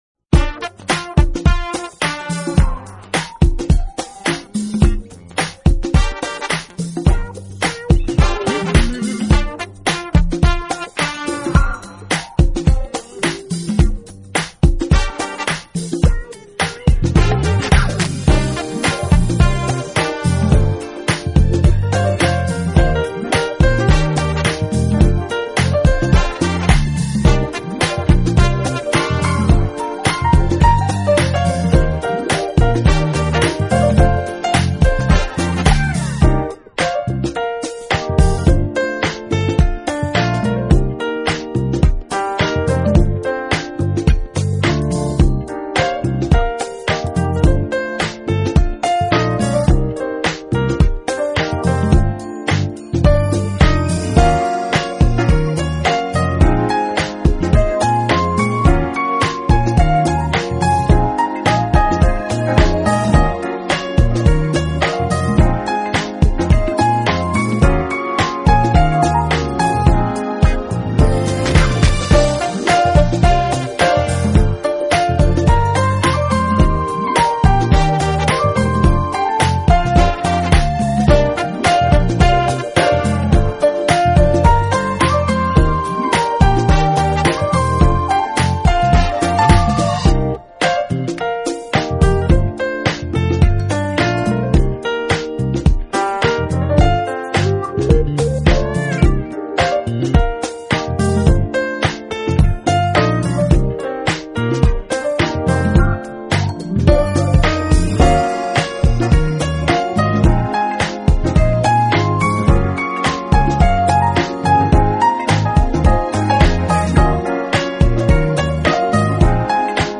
【爵士钢琴】